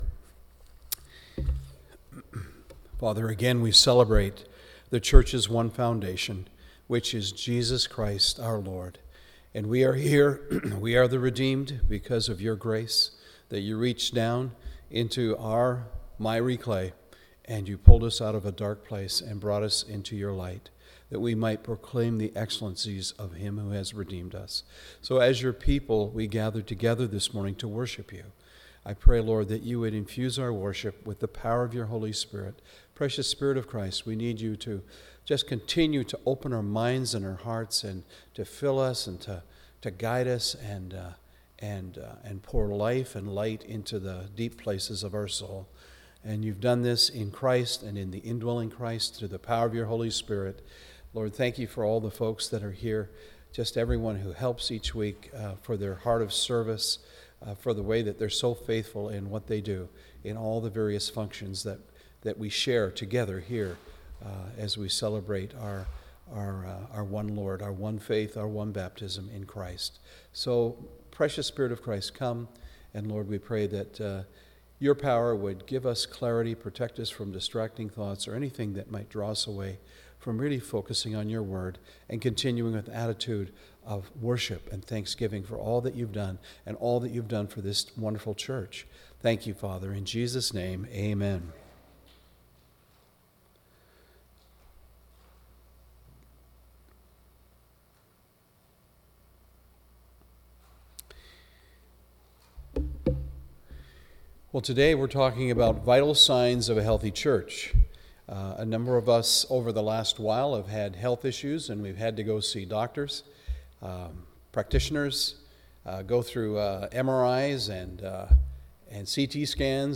Sermons | Peninsula Mission Community Church